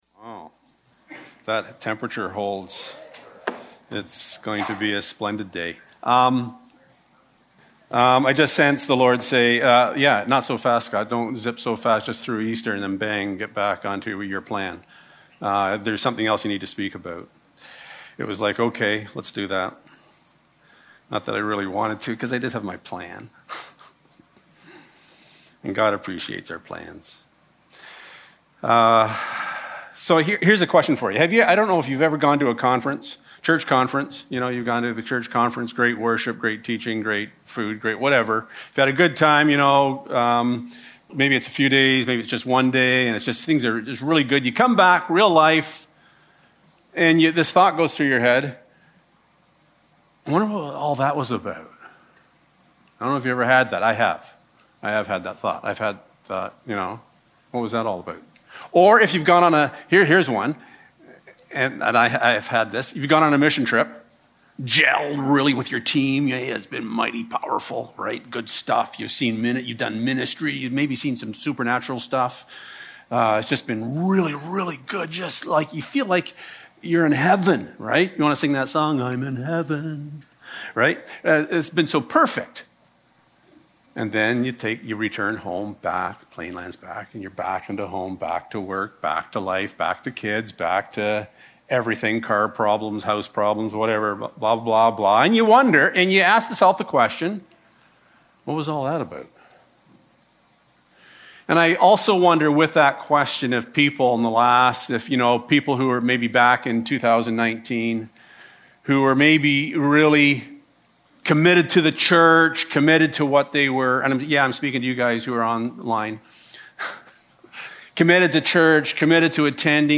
1-17 Service Type: Sunday Morning For many of us